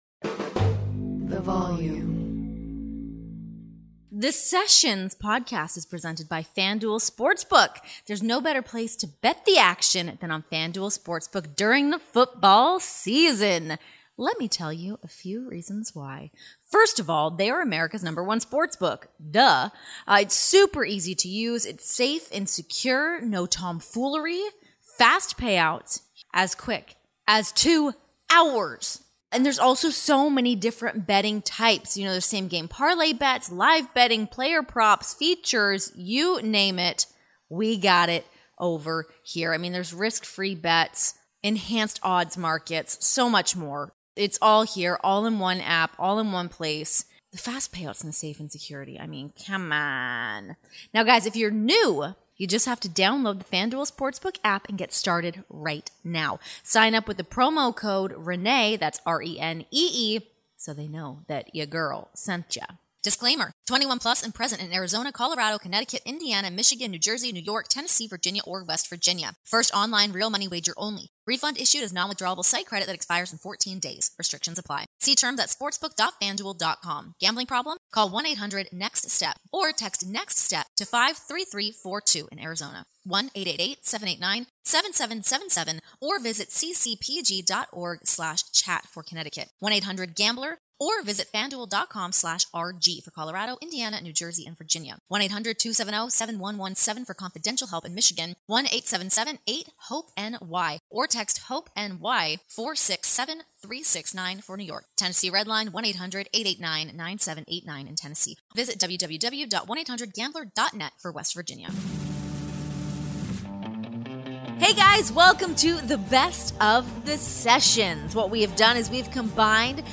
Relive the best of the Sessions this week, featuring highlights from Renee's chats with NBA Hall of Famer Charles Barkley and AEW star Kyle O'Reilly.